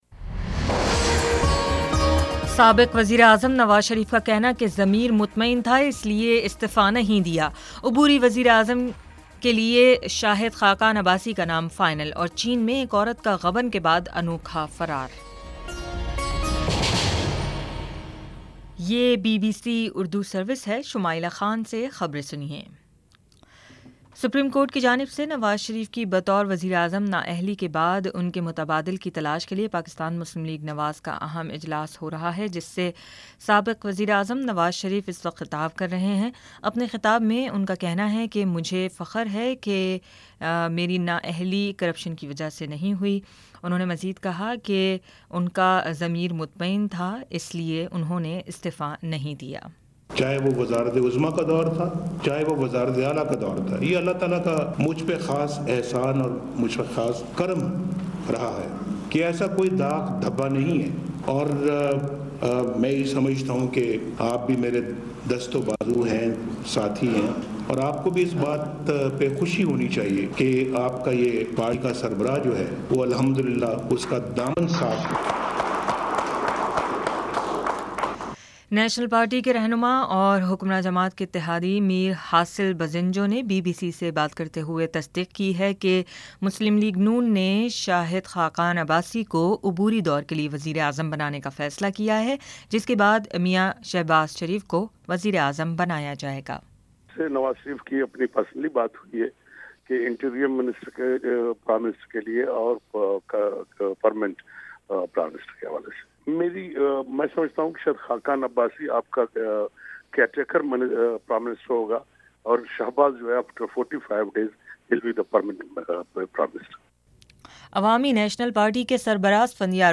جولائی 29 : شام سات بجے کا نیوز بُلیٹن